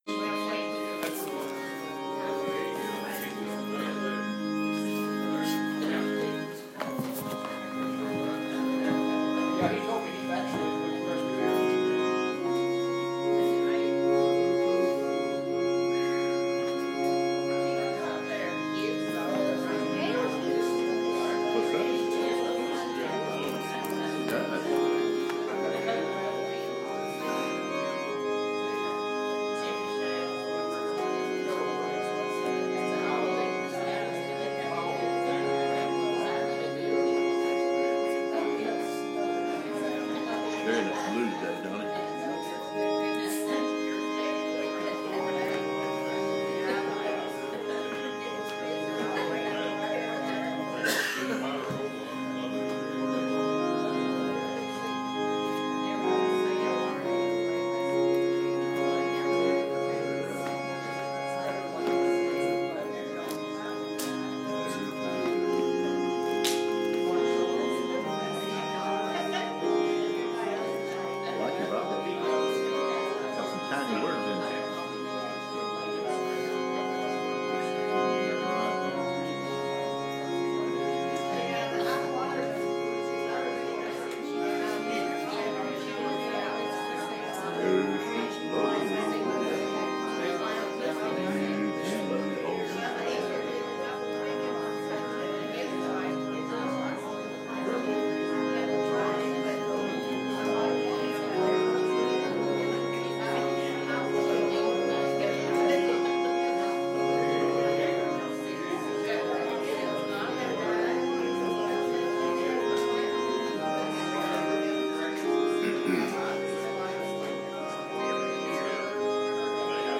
Goal of this sermon: To Share the Covenant of Grace so Others will follow Jesus.